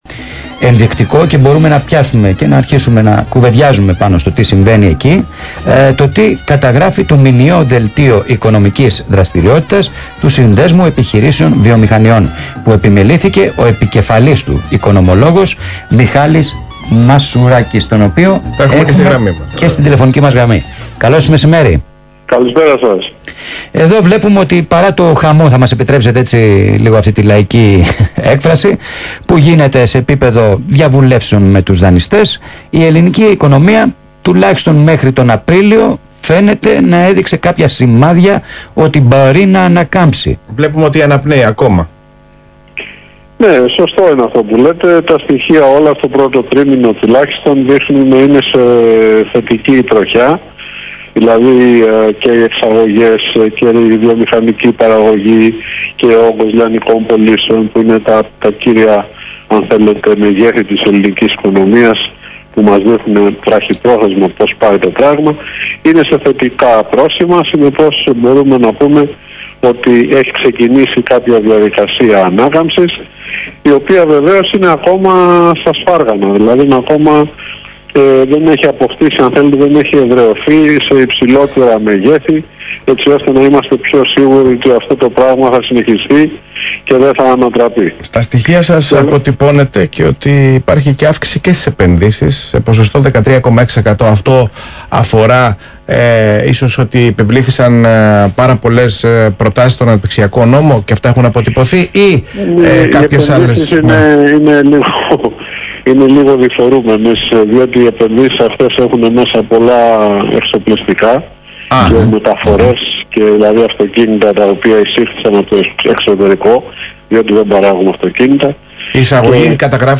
στον Ρ/Σ ALPHARADIO